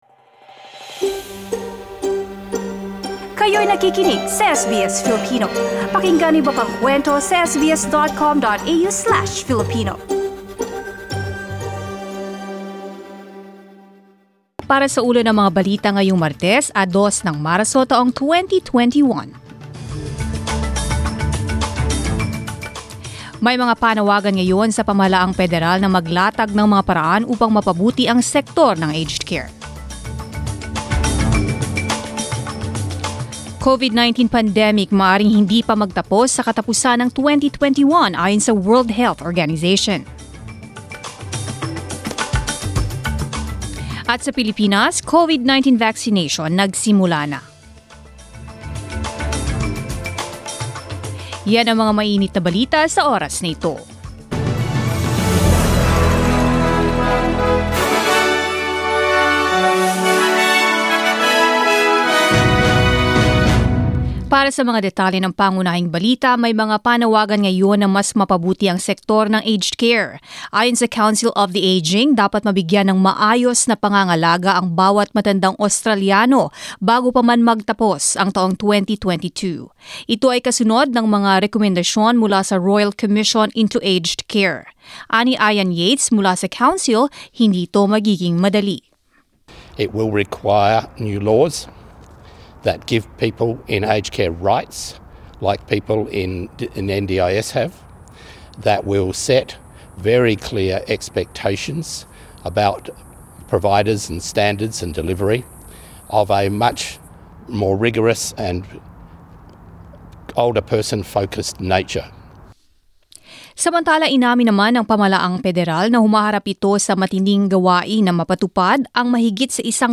Mga balita ngayong ika-2 ng Marso